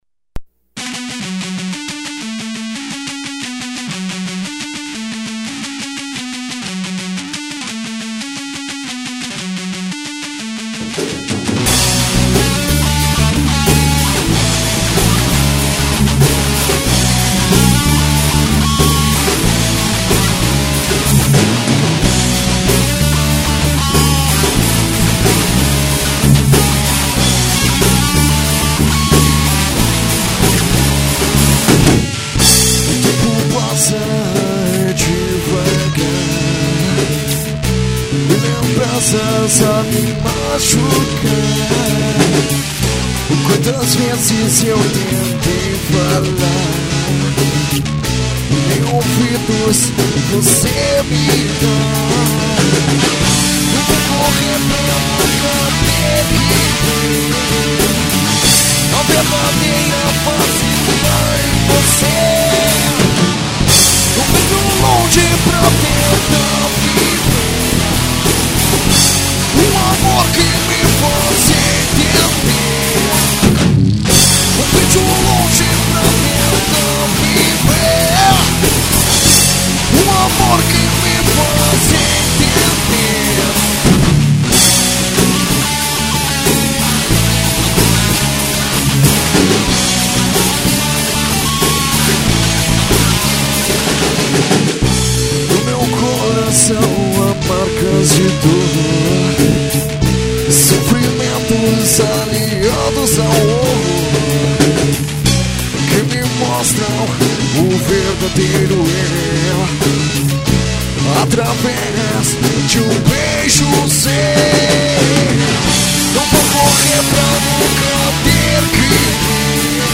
EstiloPop Rock